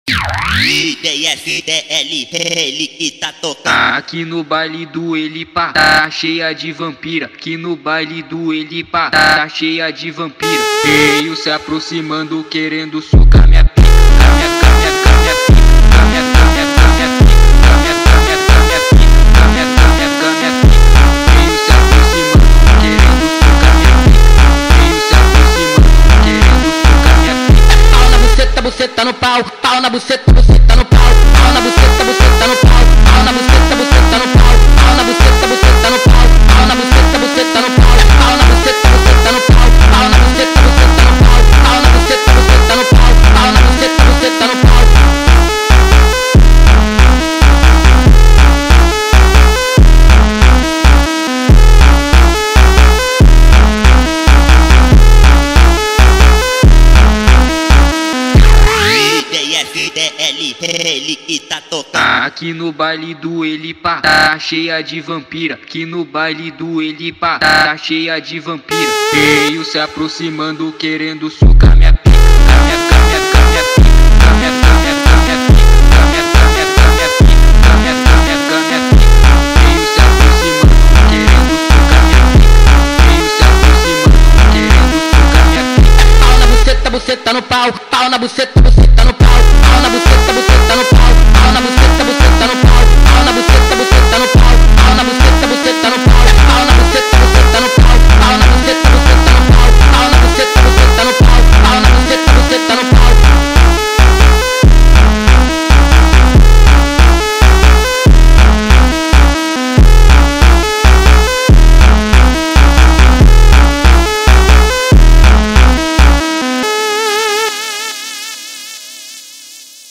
funk phonk remix